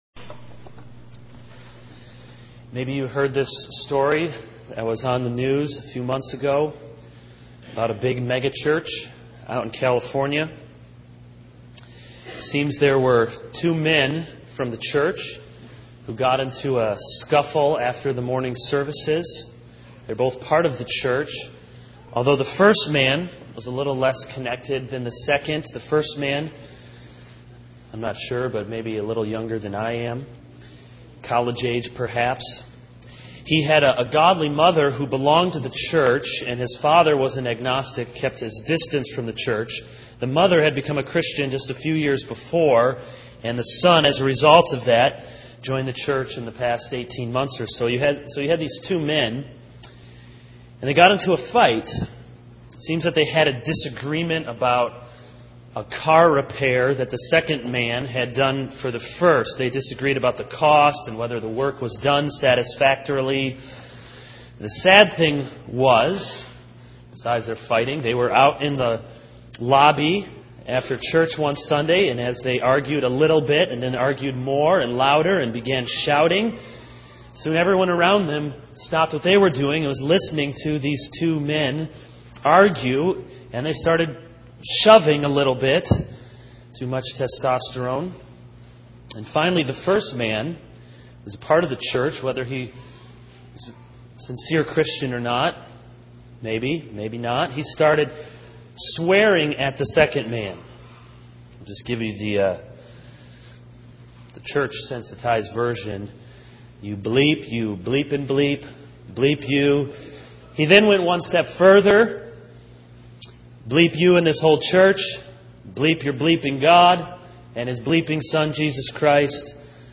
This is a sermon on Exodus 20:1-17 - Reverence God's Name.